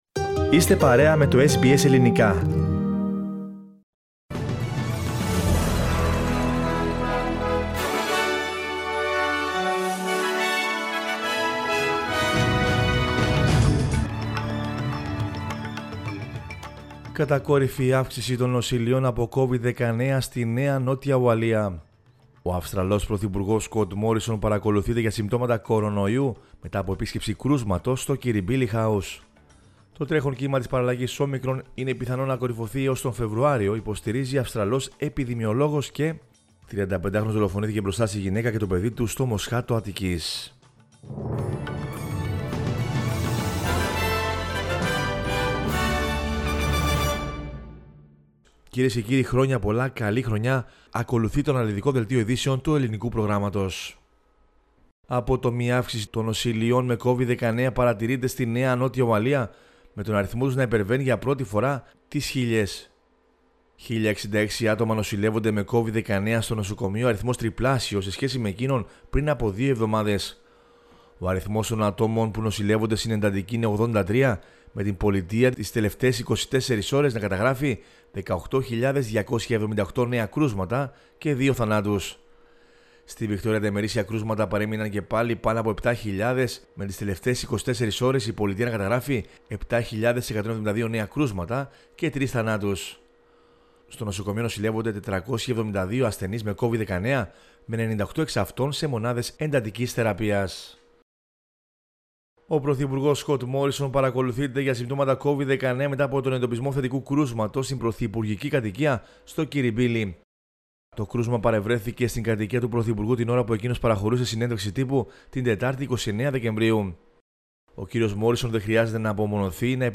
News in Greek from Australia, Greece, Cyprus and the world is the news bulletin of Sunday 2 January 2022.